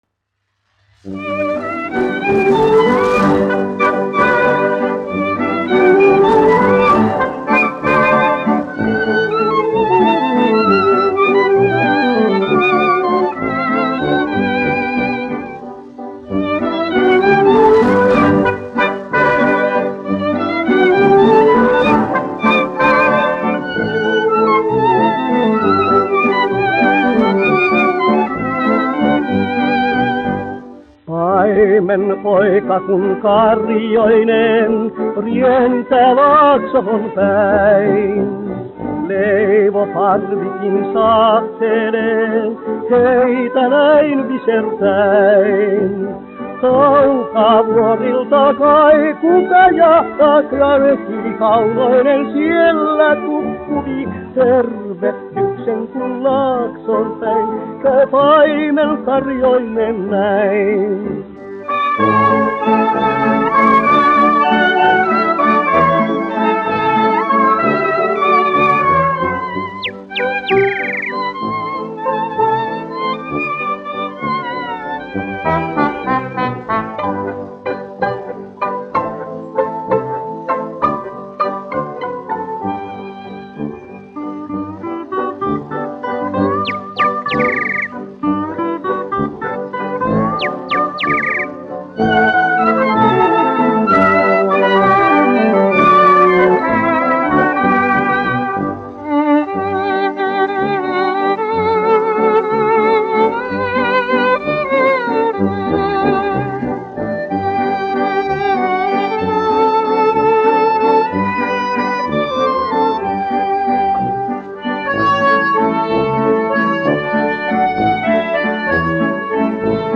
1 skpl. : analogs, 78 apgr/min, mono ; 25 cm
Valši
Populārā mūzika
Latvijas vēsturiskie šellaka skaņuplašu ieraksti (Kolekcija)